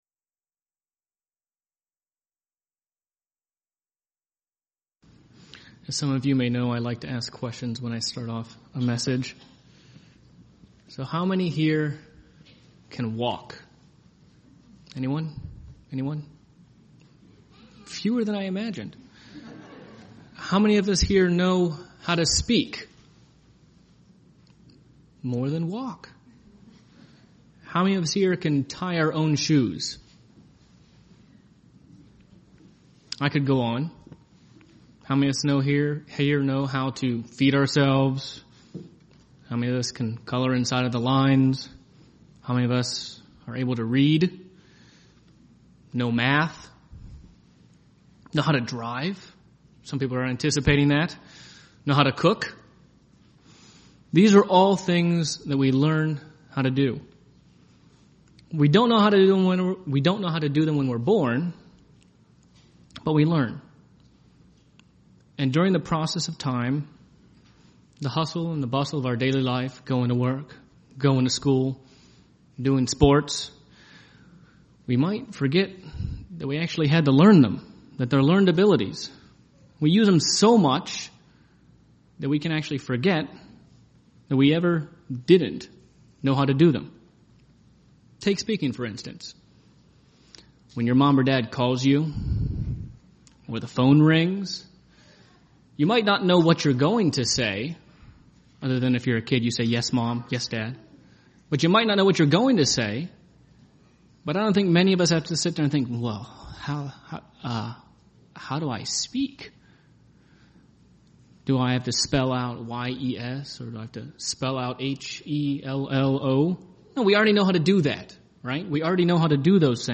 Faith UCG Sermon Studying the bible?